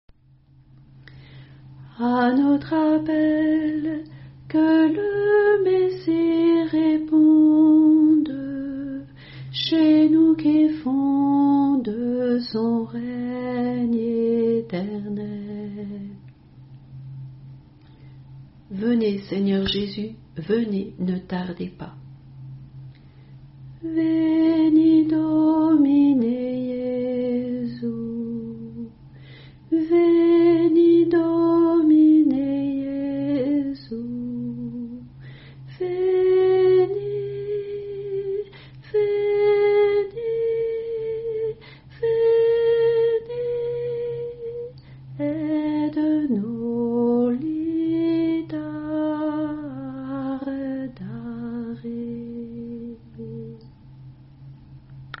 oraisons jaculatoires chantées